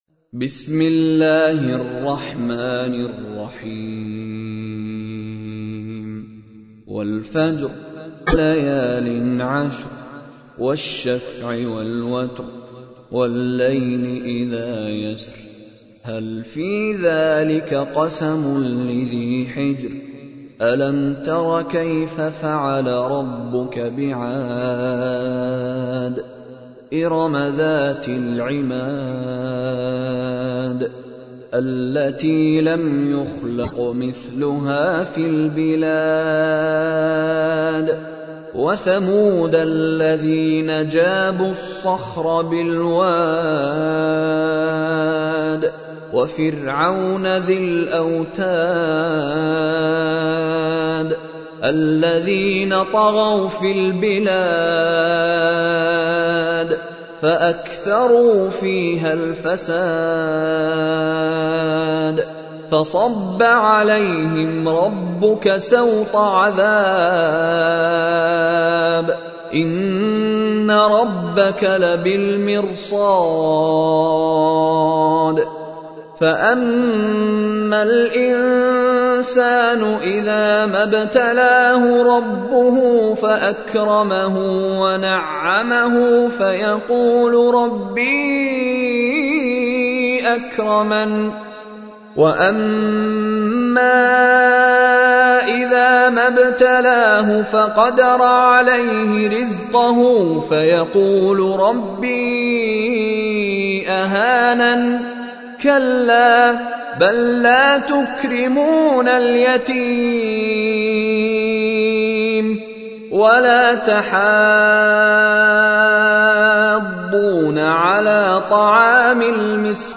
تحميل : 89. سورة الفجر / القارئ مشاري راشد العفاسي / القرآن الكريم / موقع يا حسين